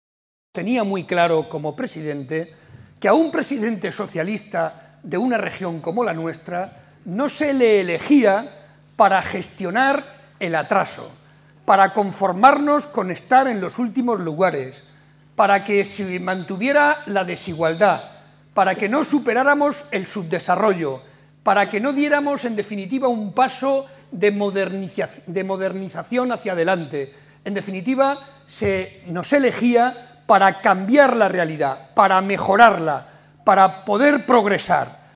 Barreda explicó durante un café-coloquio ante más de 250 personas en Pozuelo de Calatrava que la “brutal” crisis internacional, “que no tiene parangón”, excede de las posibilidades de un gobierno nacional o regional si actúa por sí sólo, por lo que se necesitará de la acción conjunta de la Unión Europea para salir adelante.
Cortes de audio de la rueda de prensa